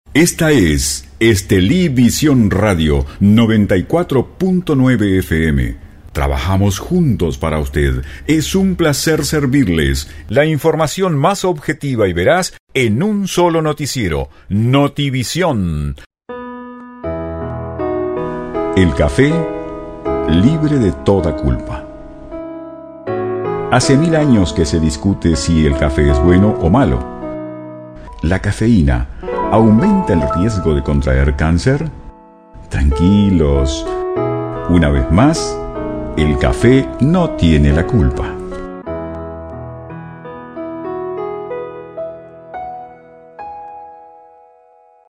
Sprecher spanisch (Südamerika). Dicción clara, firme, segura.
Sprechprobe: Industrie (Muttersprache):